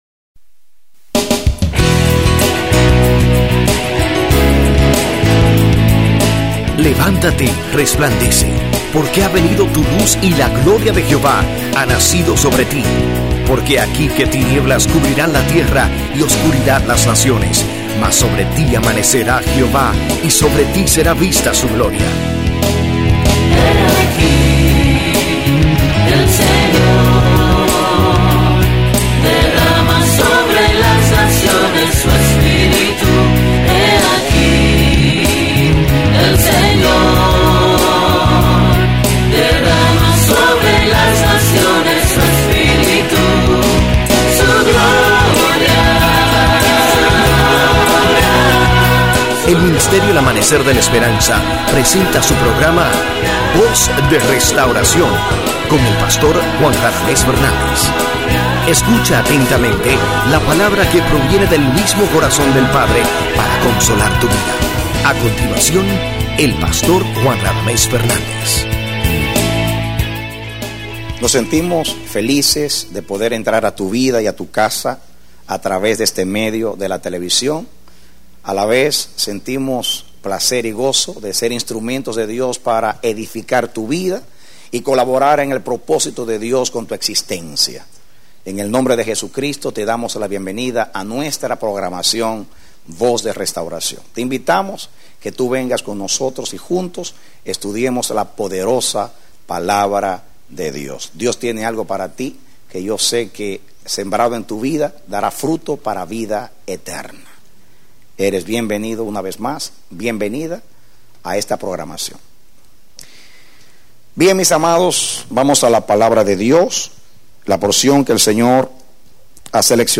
Predicado Septiembre 25, 2005